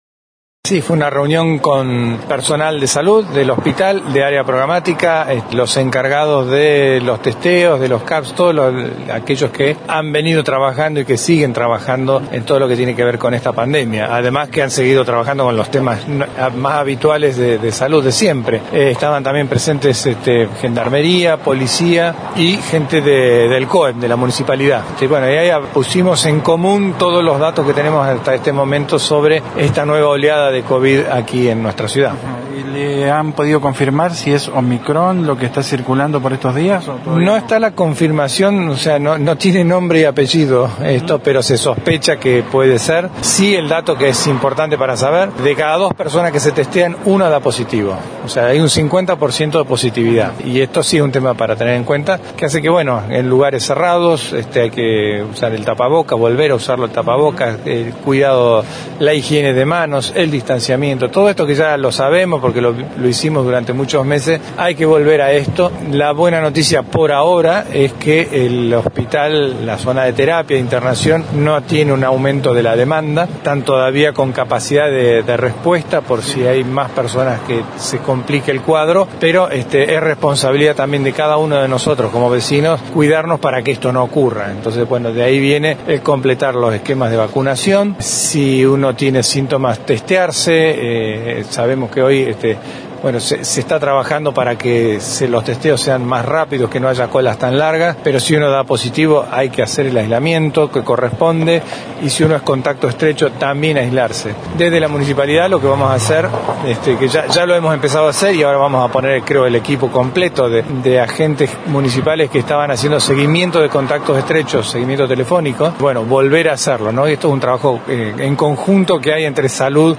Escuchá el testimonio del intendente anunciando que evalúan la suspensión de las actividades enmarcadas en un nuevo aniversario de Esquel.